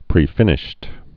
(prē-fĭnĭsht)